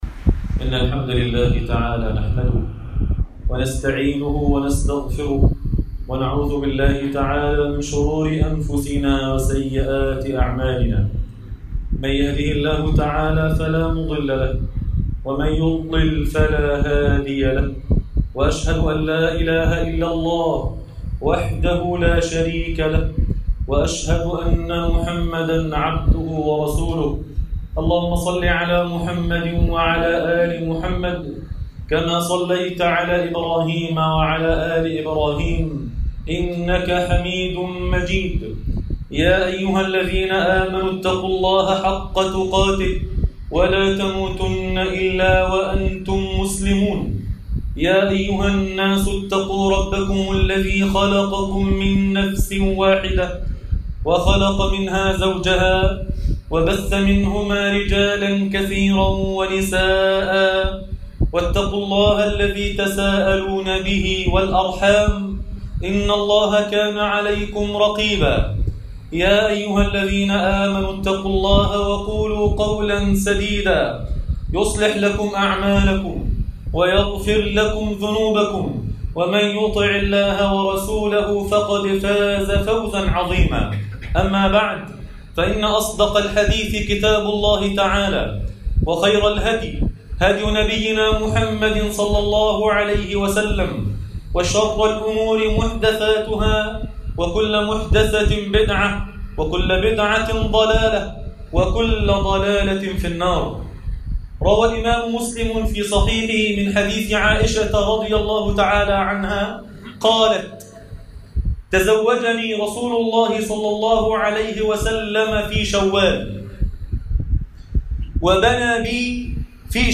الأمل في رحمة الله - خطبة جمعة